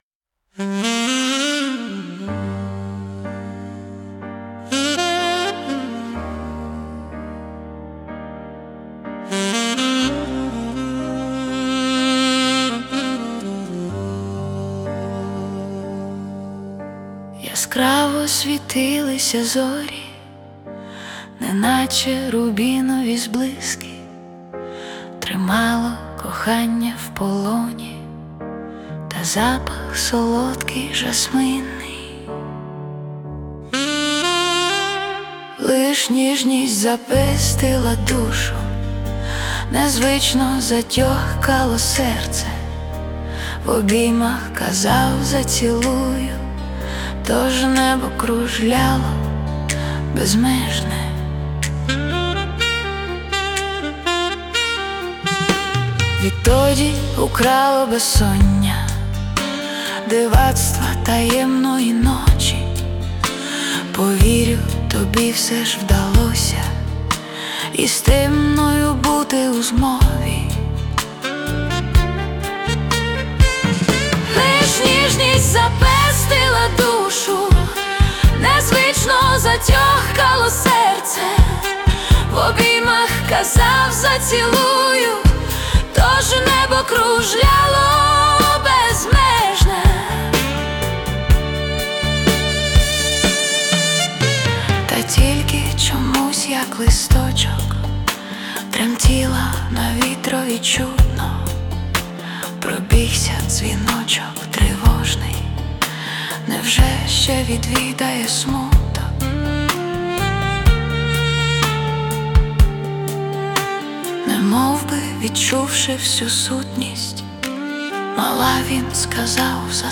Музична композиція створена за допомогою SUNO AI
СТИЛЬОВІ ЖАНРИ: Ліричний